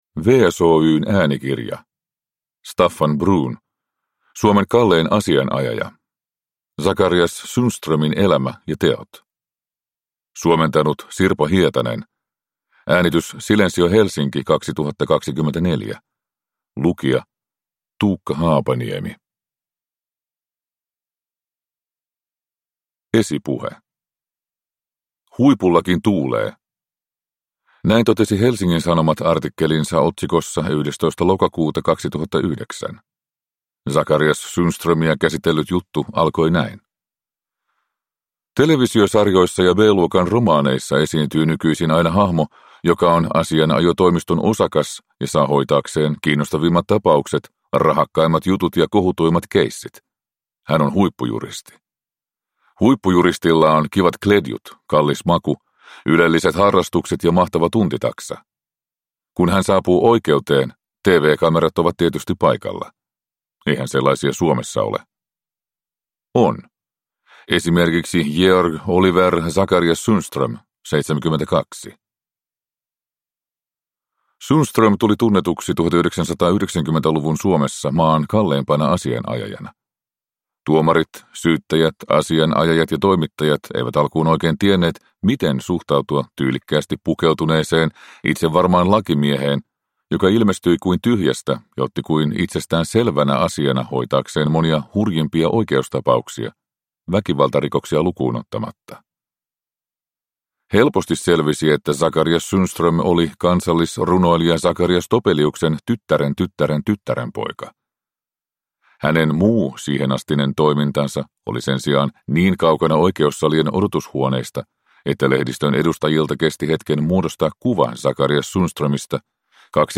Suomen kallein asianajaja (ljudbok) av Staffan Bruun